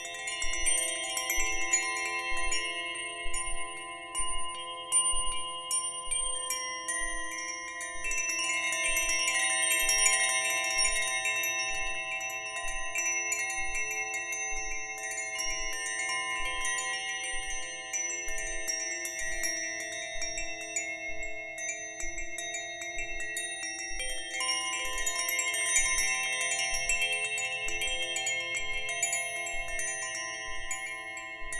Gartenklangspiel Polaris - der Klang des Nordsterns - harmonisch und fein
Ein feiner Ton erklingt, silbern, schwebend - und plötzlich scheint die Welt stillzustehen.
Die fein gestimmten Töne weben ein Klangbild voller Leichtigkeit und Geborgenheit.
Der Klang umhüllt Dich wie sanftes Licht auf ruhigem Wasser.
Der elegante Klangkörper beherbergt acht silbergelötete Stahlstäbe, präzise gestimmt und kunstvoll verbunden. Die Aluminiumglocke wirkt als Resonanzkörper und schenkt jedem Ton seine unverwechselbare Tiefe und Klarheit.
Stimmung: E F G C E F A C - 432 Hz